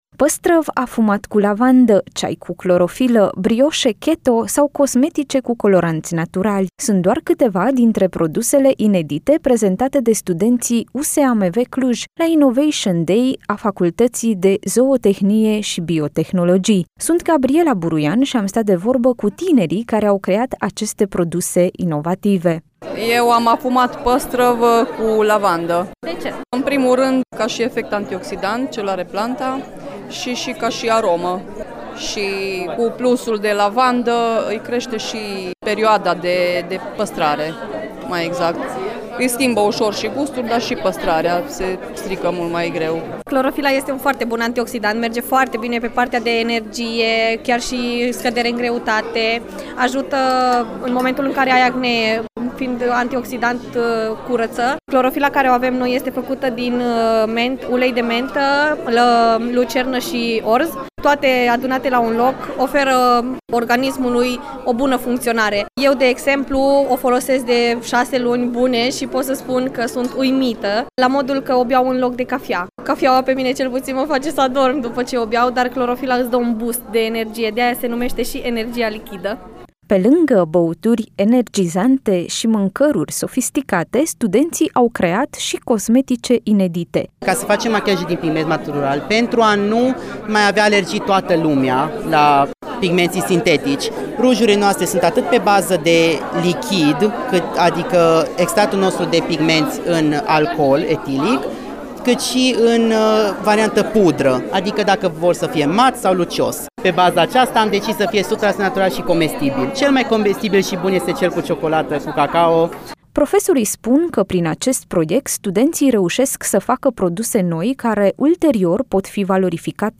a fost la Innovation Day și a vorbit cu studenți și profesori.